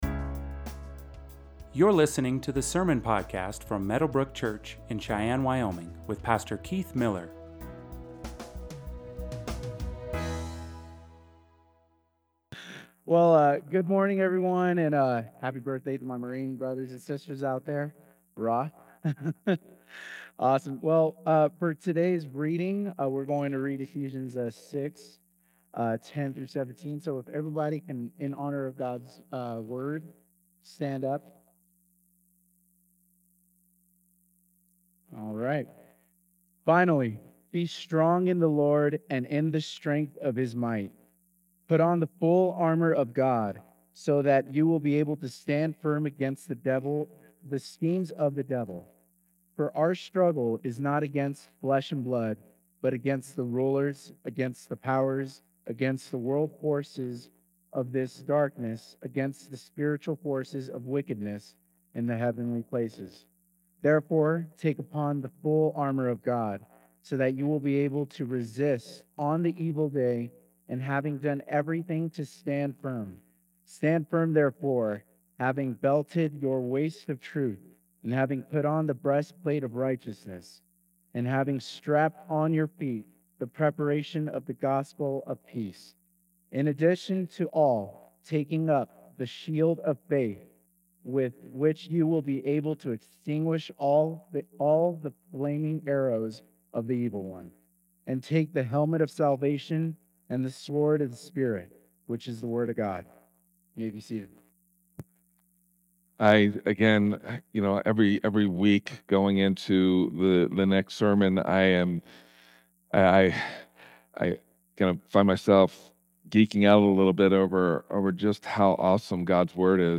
Sermons | Meadowbrooke Church